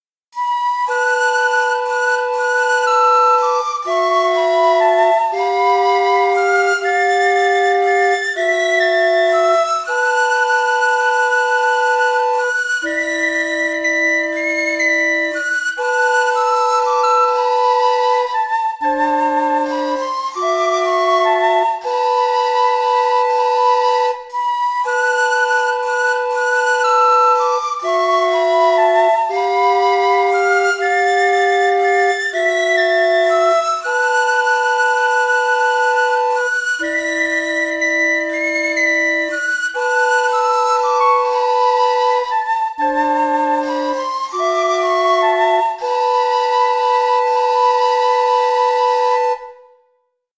It’s still in a baby stage and he said he wants to expand on it, but here it is for now!